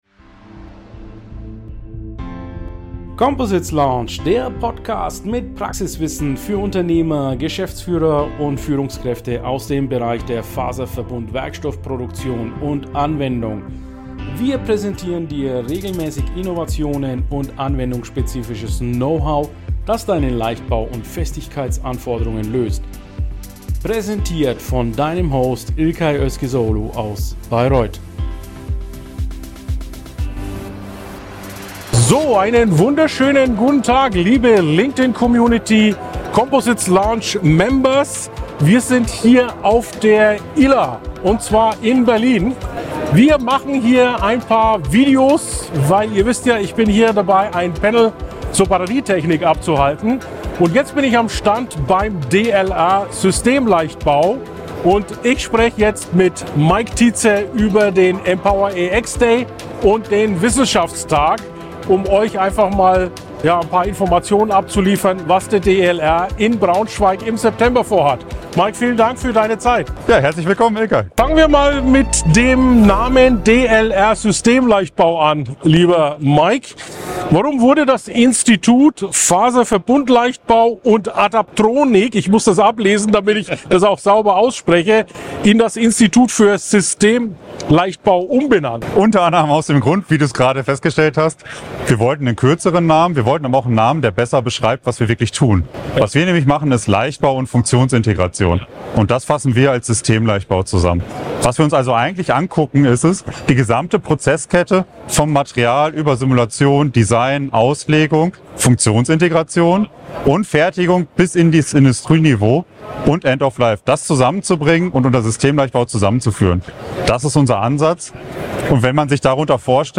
auf dem DLR Messestand während der ILA 2024 in Berlin